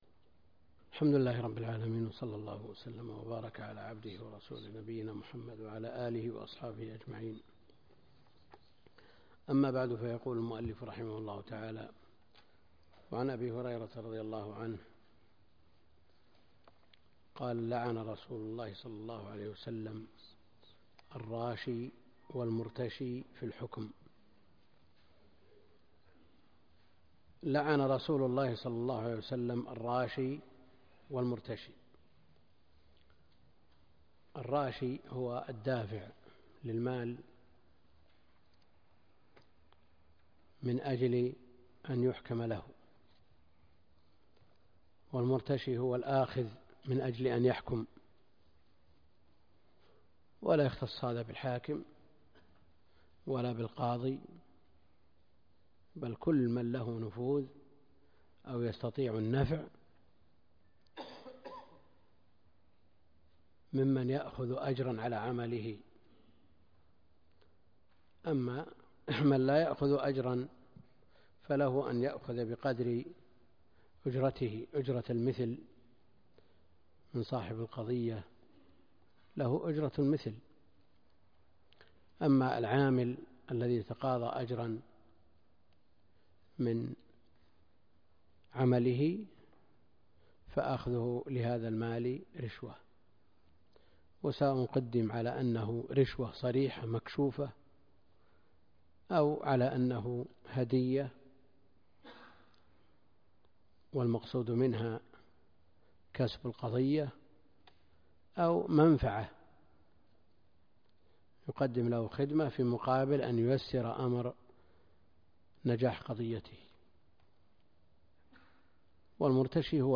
الدرس (3) كتاب القضاء من بلوغ المرام - الدكتور عبد الكريم الخضير